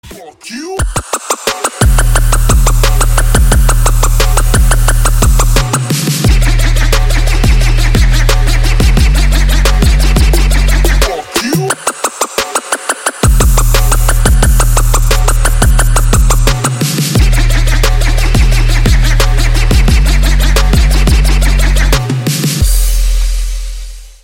Elektronisk musik, Android